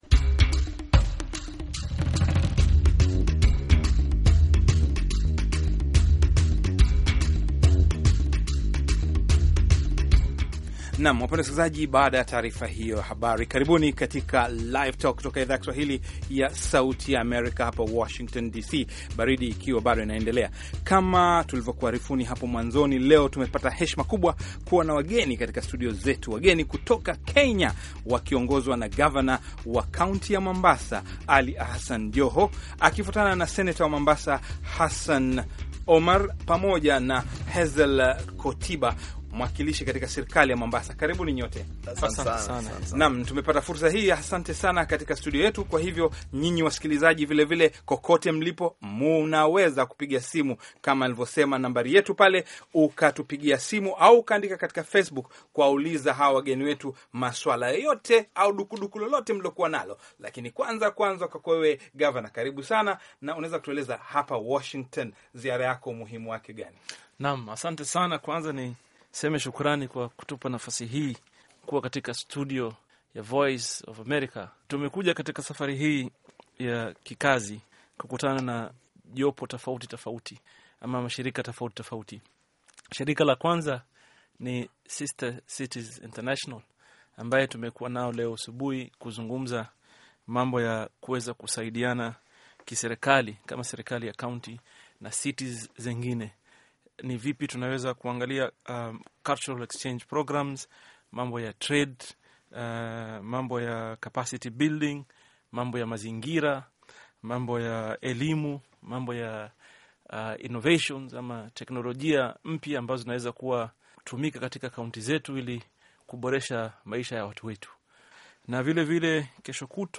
Live Talk: Mazungumzo na Gavana Joho